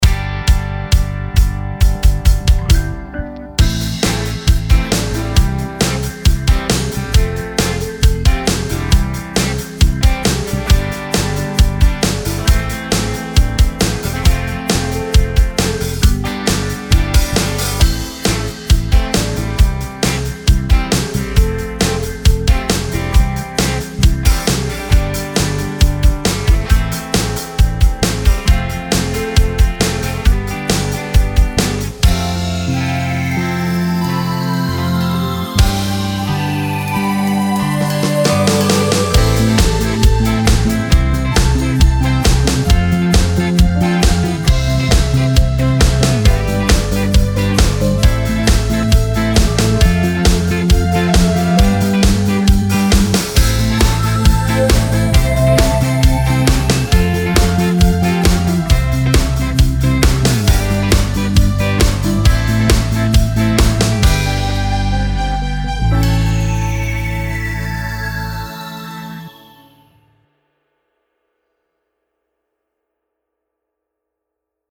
Musikbakgrund